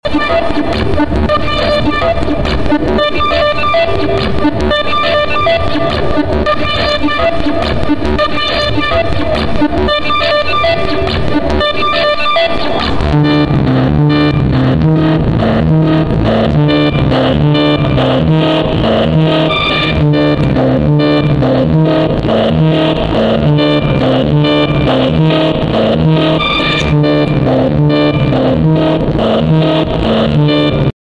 my tom talking glitch 1999 sound effects free download